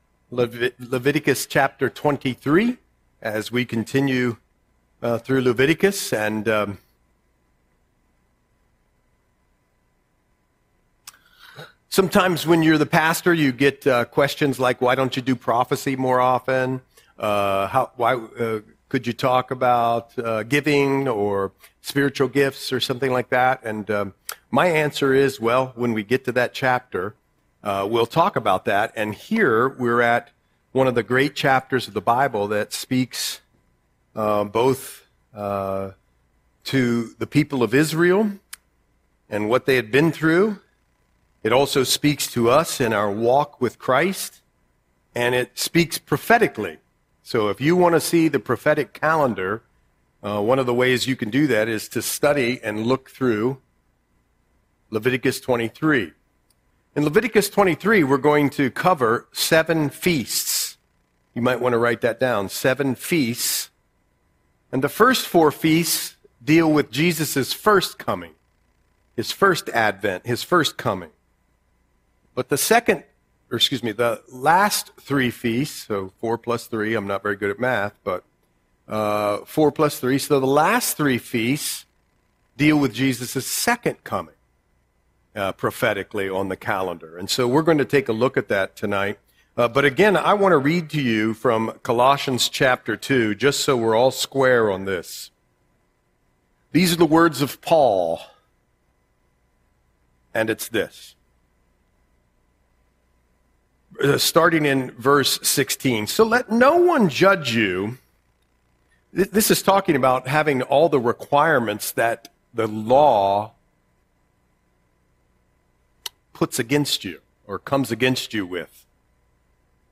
Audio Sermon - January 7, 2026